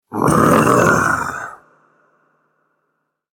Rawr Sound Effect
This stylized roar inspired by cartoon monsters, dinosaurs, or meme culture adds humor and energy to any project. Rawr sound effect is ideal for comedic scenes, animations, games, funny transitions, or internet-style content, bringing playful character and a quirky twist to your audio or video productions.
Rawr-sound-effect.mp3